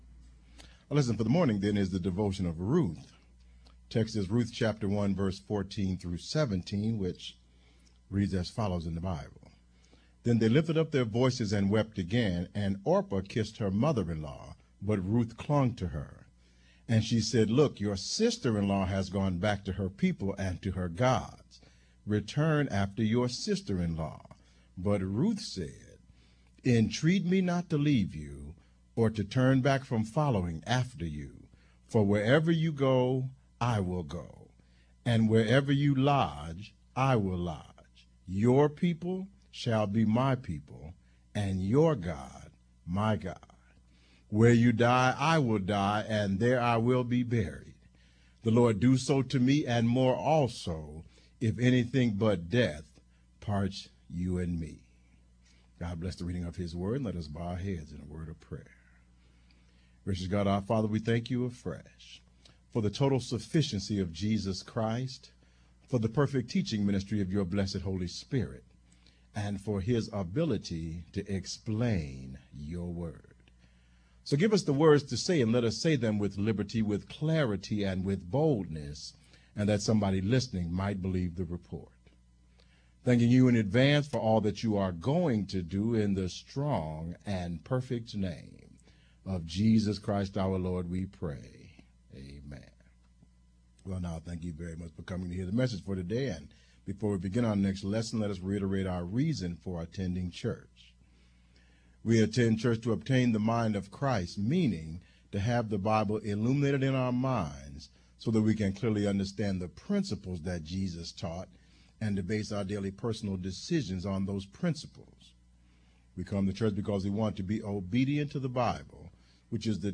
Audio Download: Click to download Audio (mp3) Additional Downloads: Click to download Sermon Text (pdf) Content Feeds Use the links below to subscribe to our regularly produced audio and video content.